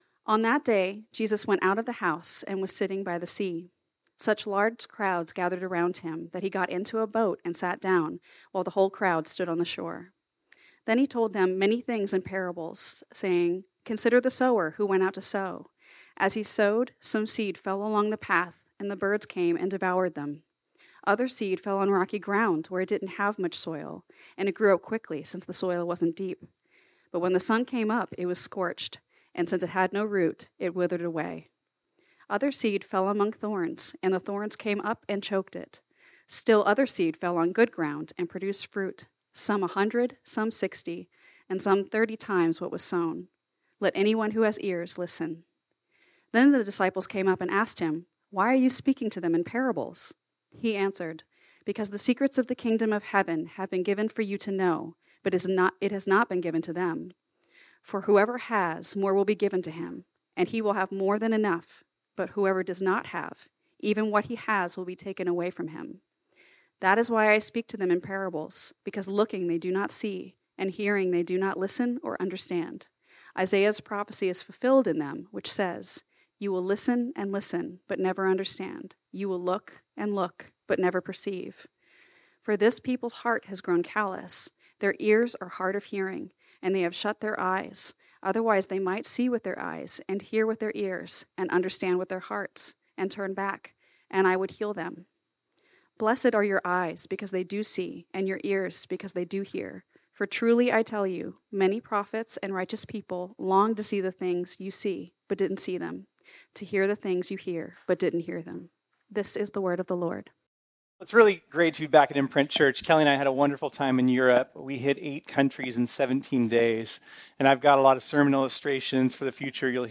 This sermon was originally preached on Sunday, June 9, 2024.